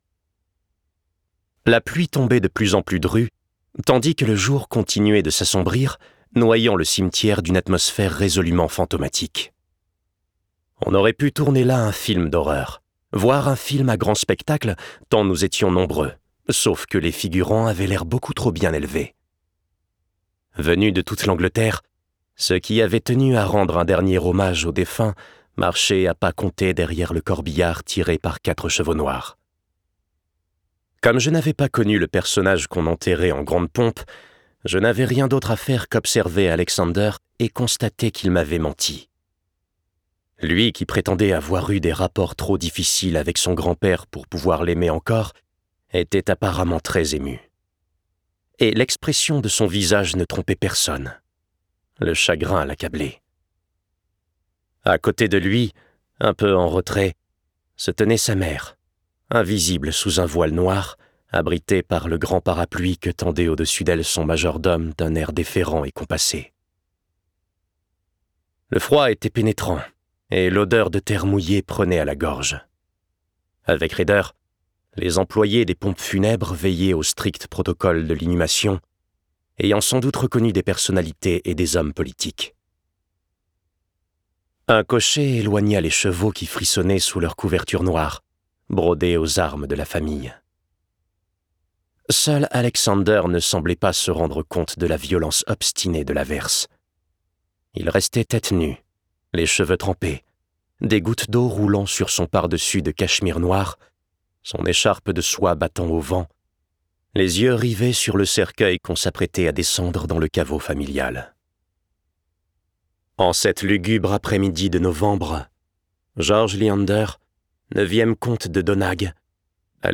je découvre un extrait - Objet de toutes les convoitises de Françoise BOURDIN